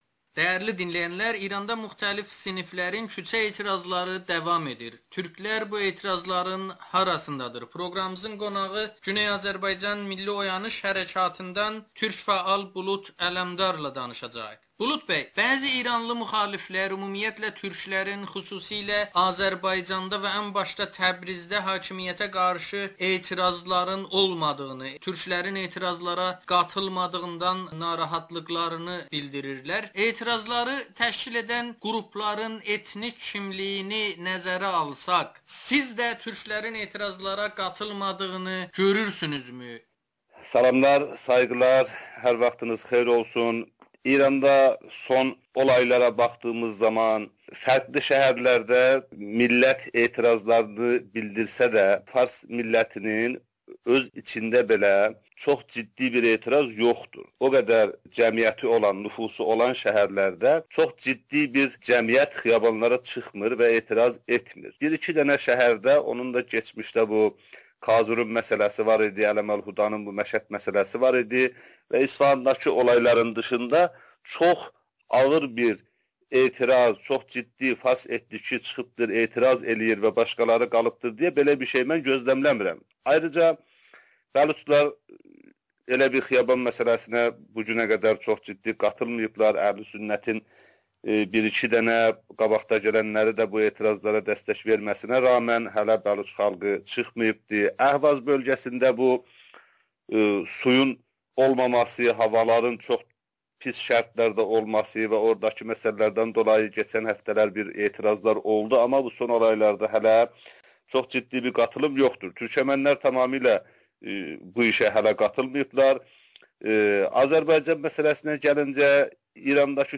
İranda etirazlar - türklər niyə ehtiyatlı davranır? [Audio-Müsahibə]